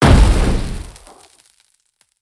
spell-impact-5.ogg